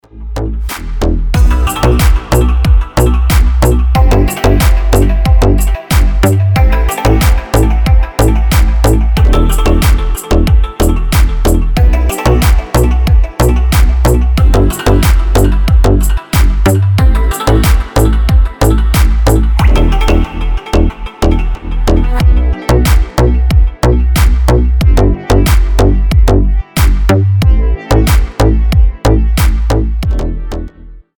Arabic deep